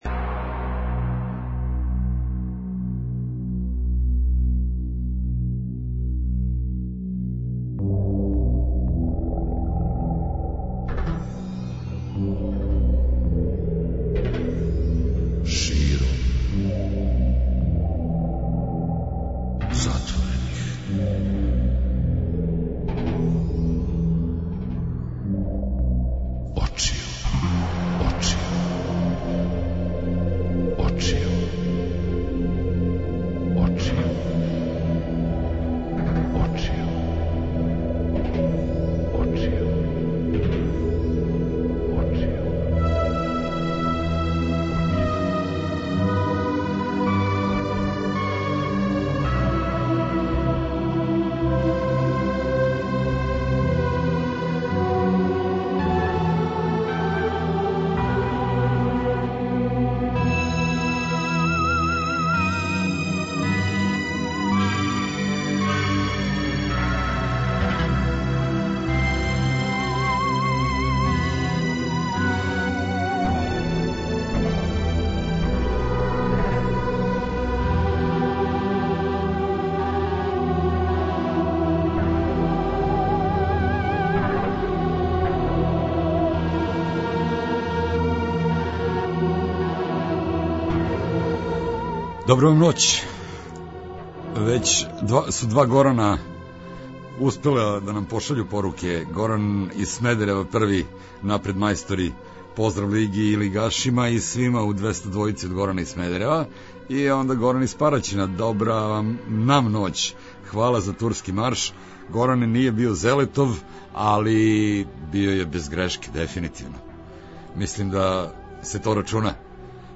Лига 202 и Куп 202 - спој добре рок музике, спортског узбуђења и навијачких страсти.
преузми : 57.31 MB Широм затворених очију Autor: Београд 202 Ноћни програм Београда 202 [ детаљније ] Све епизоде серијала Београд 202 Говор и музика Састанак наше радијске заједнице We care about disco!!!